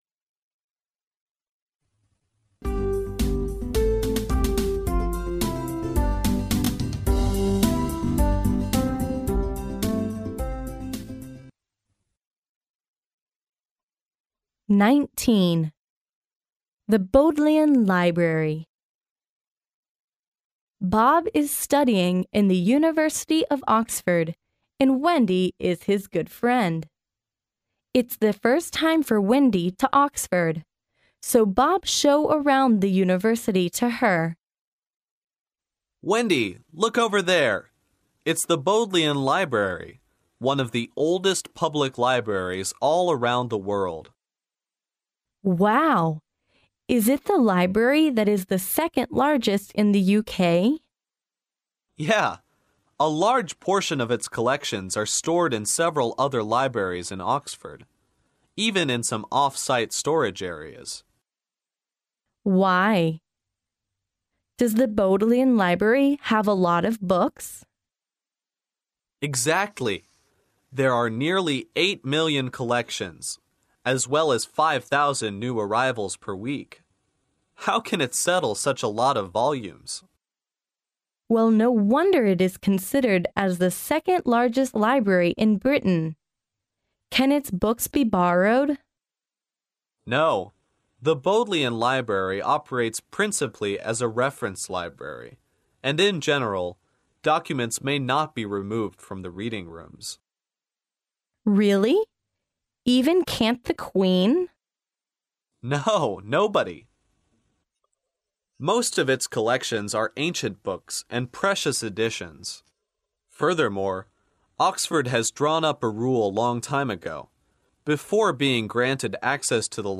牛津大学校园英语情景对话19：哈利波特的魔法学校---牛津学图书馆（mp3+中英）